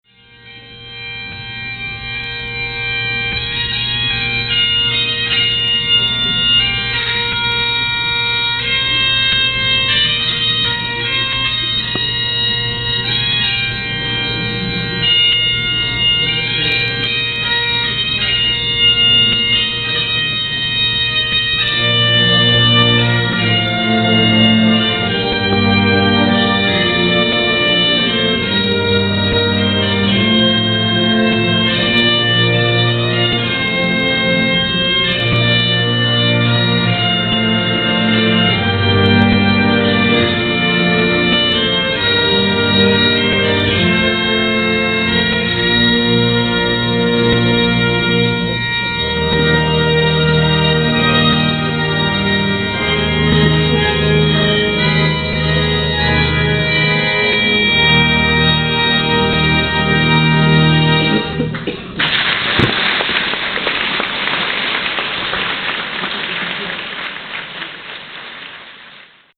Concert à bord / Brest
Musique des équipages de la Flotte
Extrait concert / Final / Format MP3 - 1 Mo